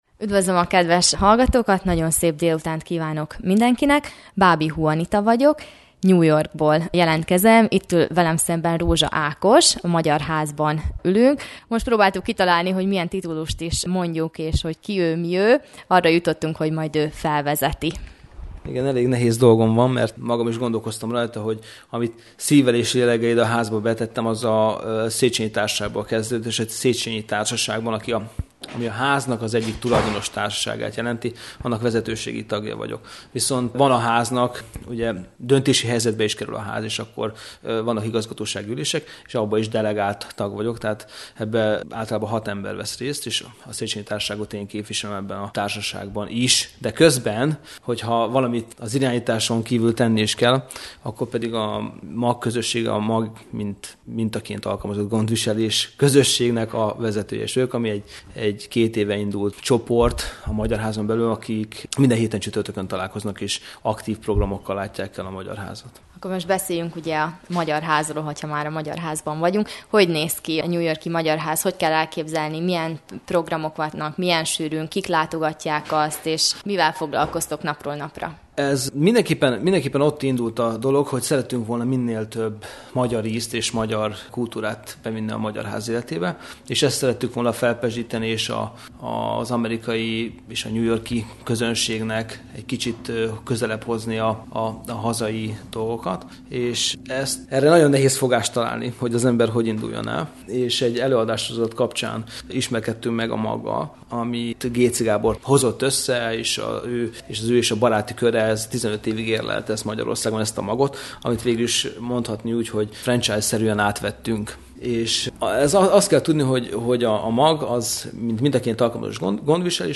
interjú közben a New York-i Magyar Házban